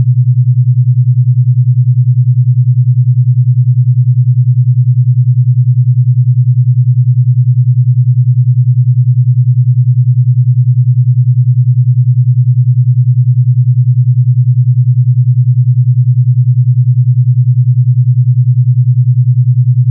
With the following track said to train the brain to produce Alpha Waves (7.5 to 14Hz) at 10Hz